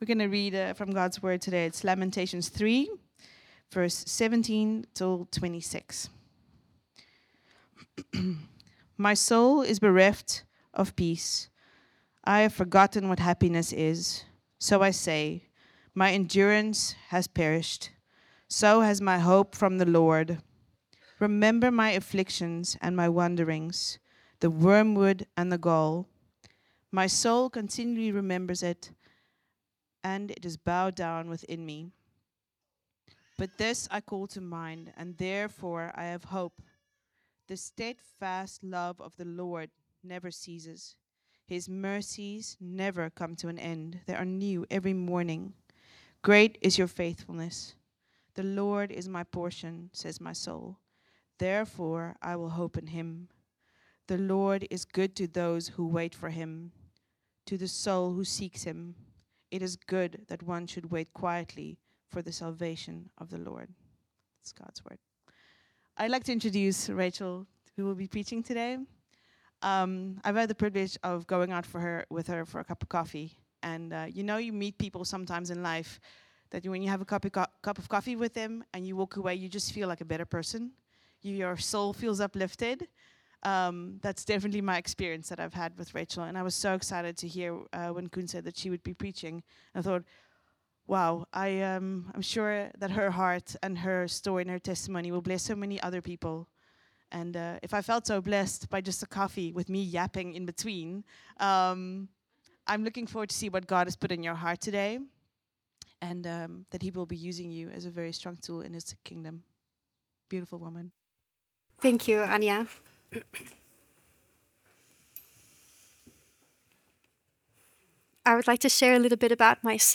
Vineyard Groningen Sermons What does it mean to walk in His unconditional and steadfast love on a daily basis?